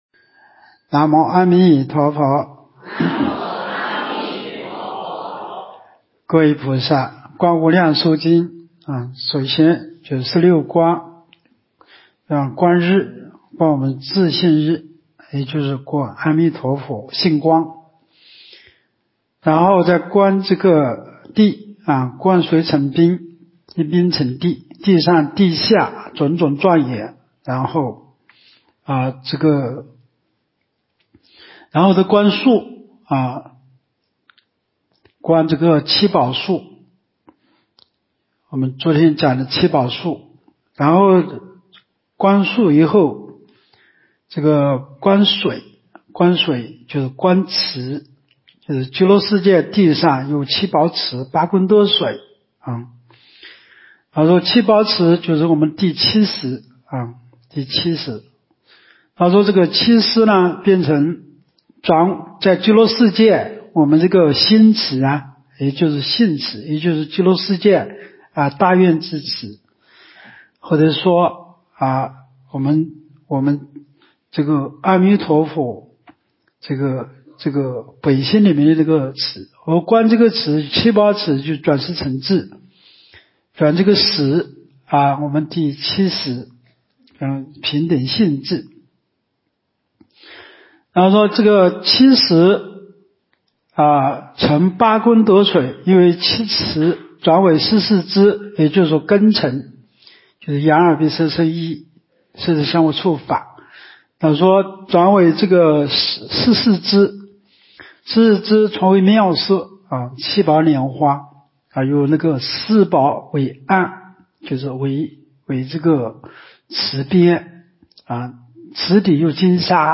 无量寿寺冬季极乐法会精进佛七开示（23）（观无量寿佛经）...